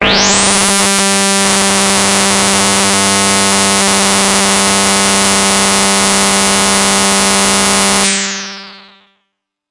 描述：通过Modular Sample从模拟合成器采样的单音。
标签： FSharp4 MIDI音符-67 DSI-利 合成器 单票据 多重采样
声道立体声